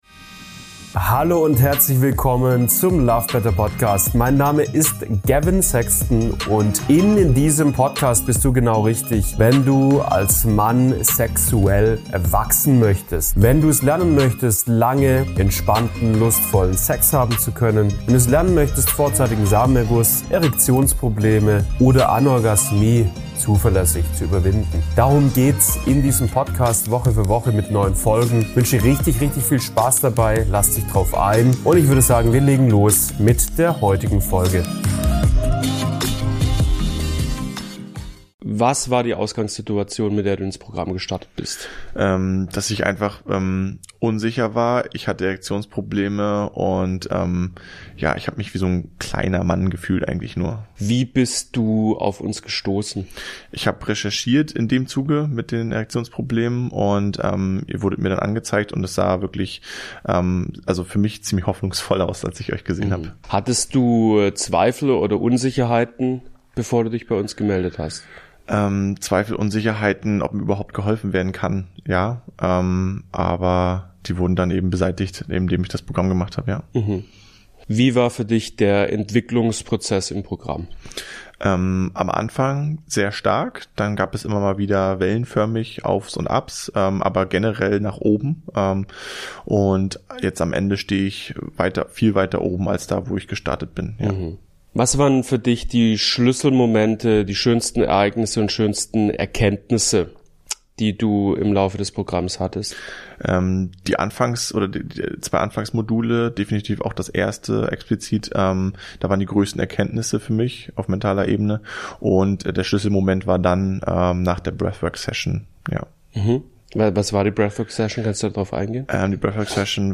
Kurzinterview